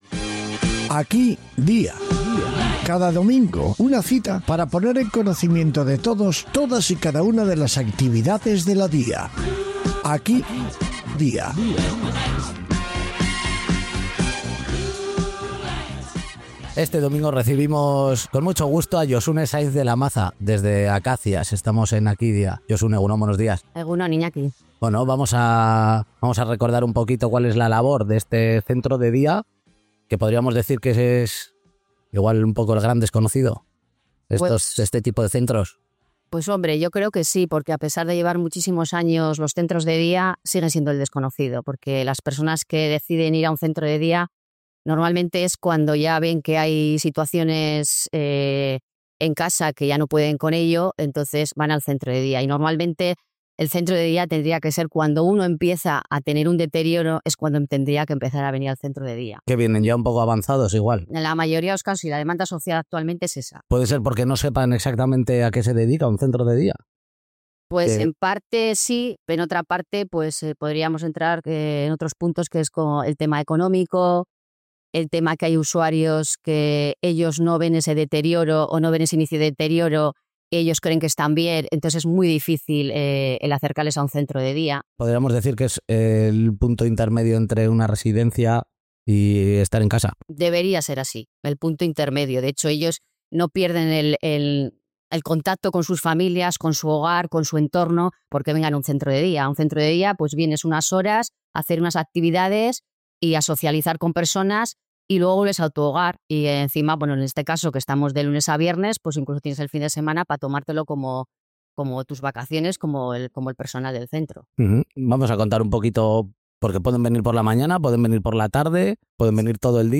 AKi-DYA 46 Visitamos de nuevo el centro de día "Acacias" en Getxo.